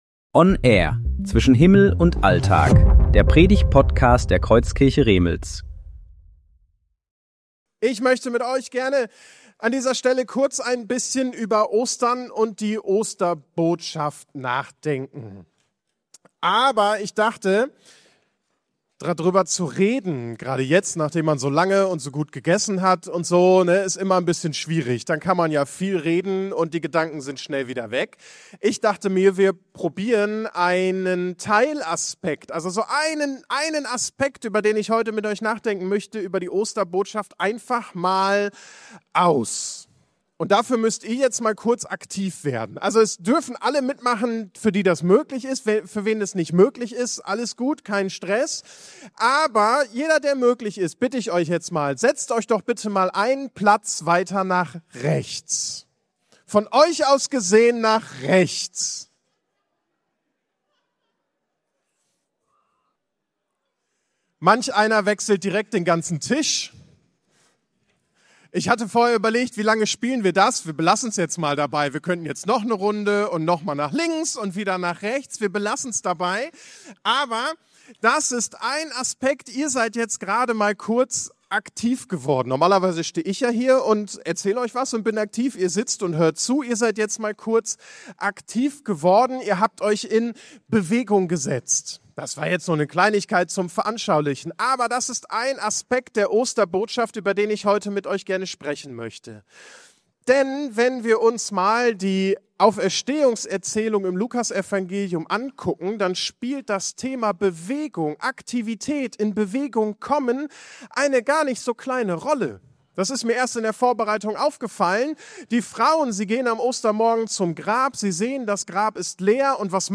Predigten
Predigtserie: Gottesdienst